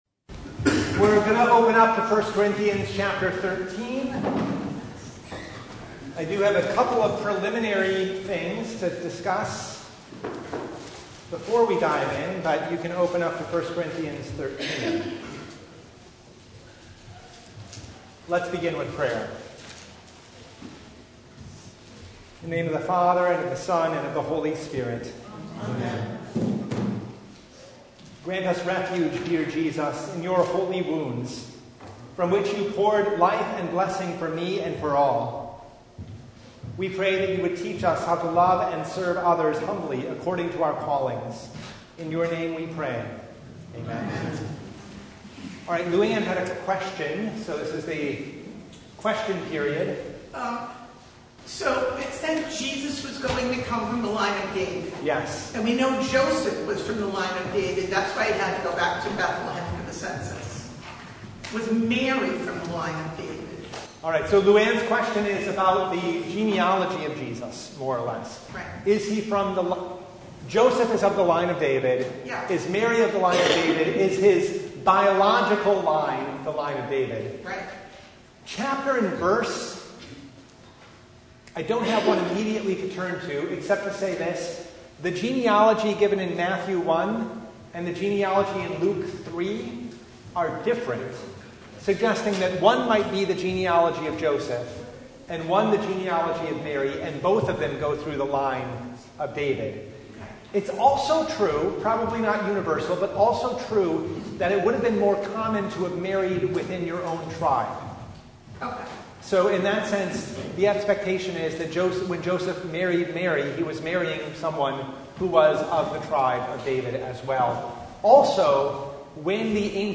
Bible Study « Who’s Really Lost